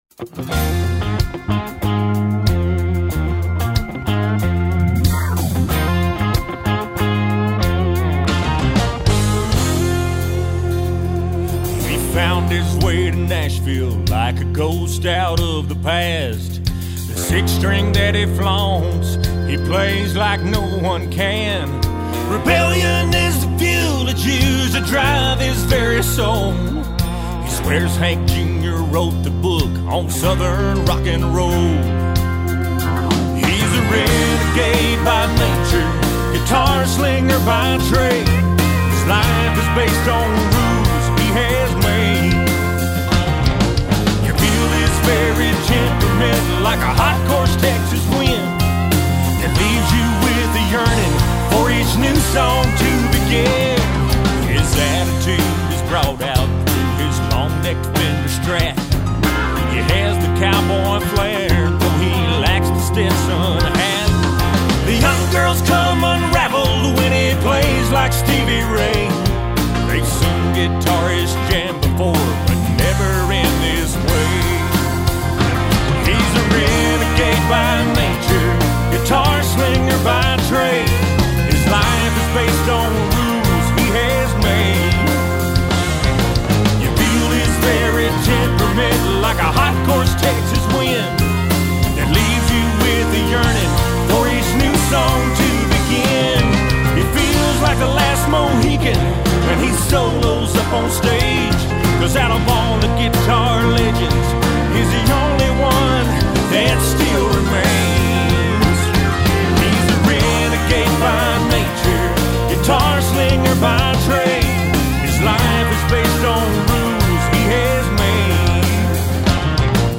A Rockin’ Country Demo